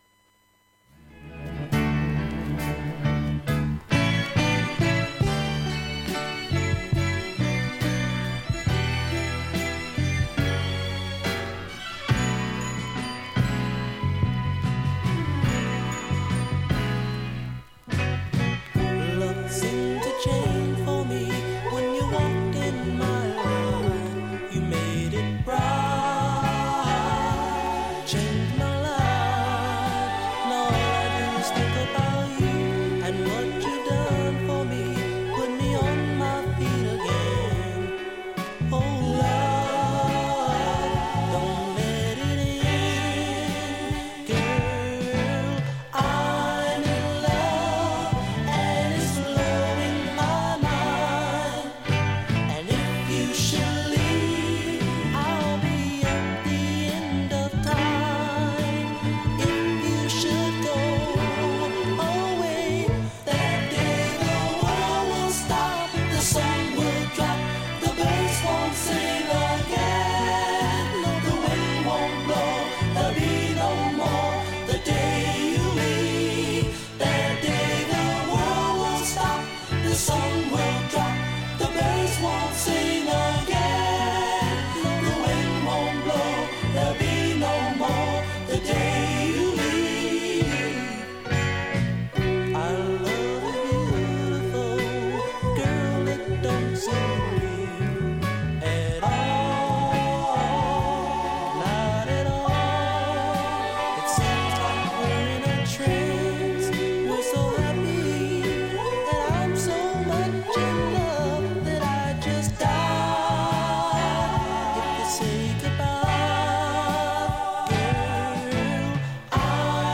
盤面きれいで音質良好全曲試聴済み
にかすかなプツが１８回出ます。